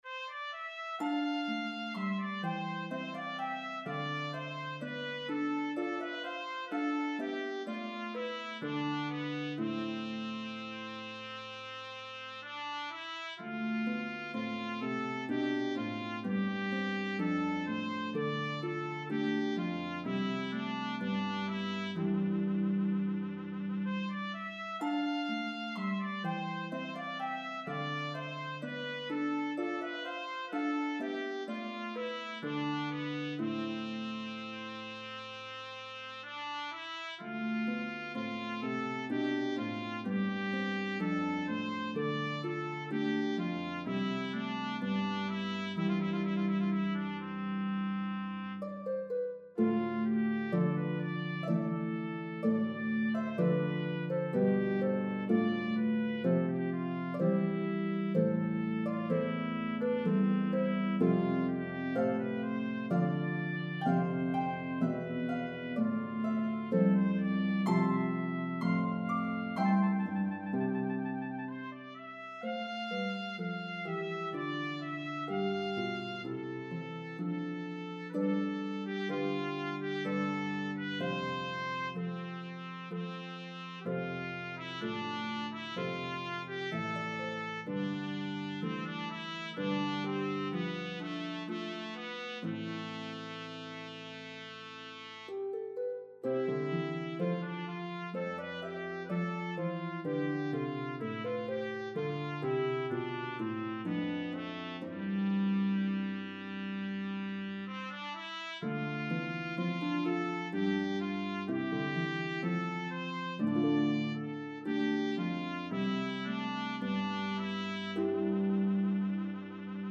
Italian Baroque style
lovely slow air